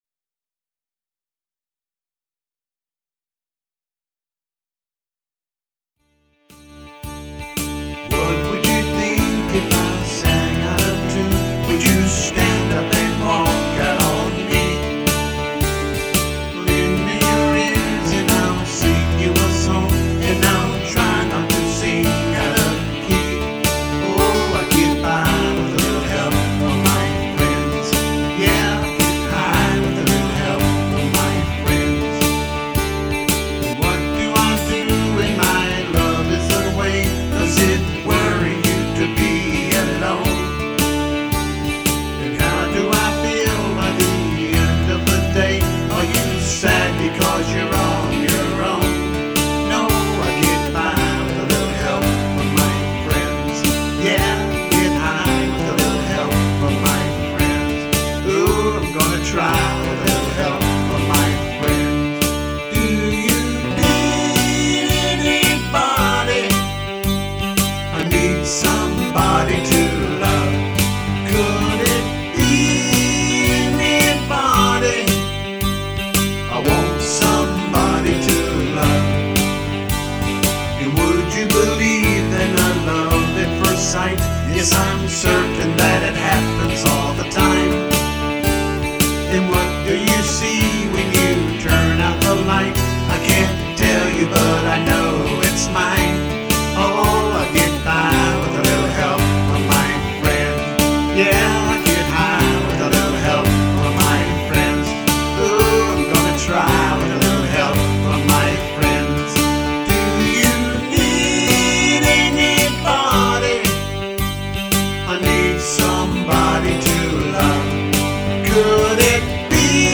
AT THE STAE FAIR OF TEXAS 2010